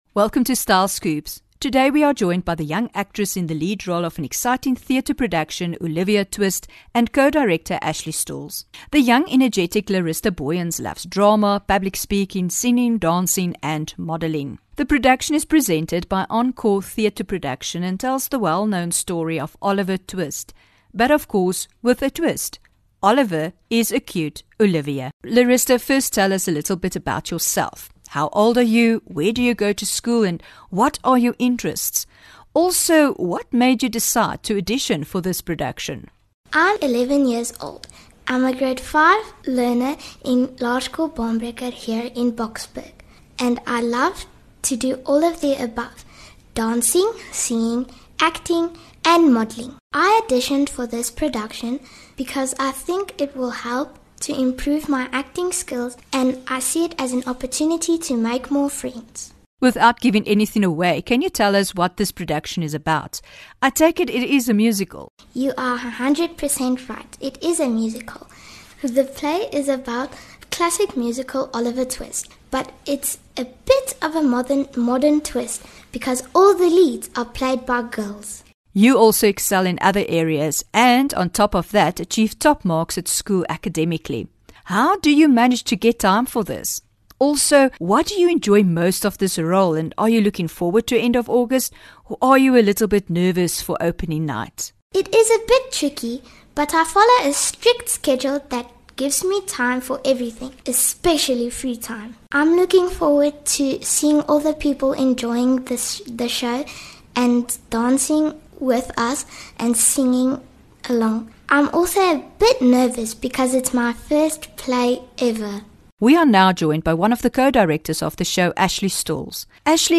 19 Aug INTERVIEW: OLIVIA TWIST THE MUSICAL